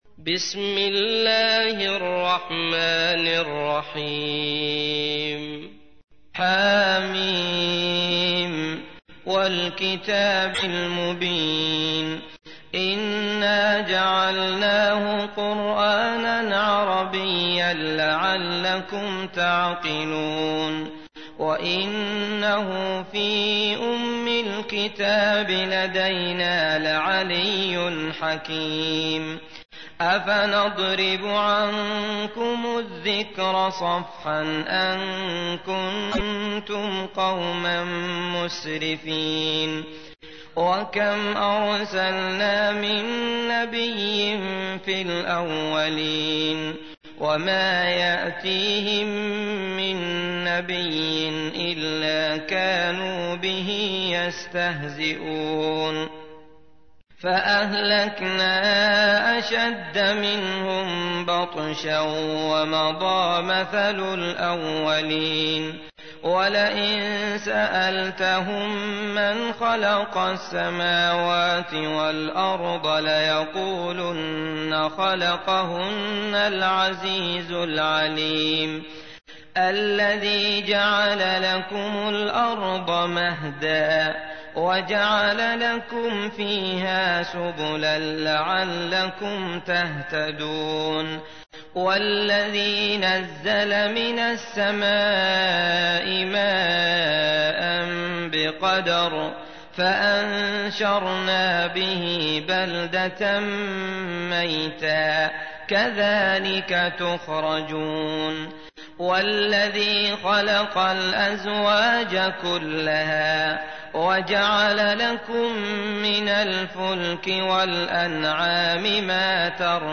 تحميل : 43. سورة الزخرف / القارئ عبد الله المطرود / القرآن الكريم / موقع يا حسين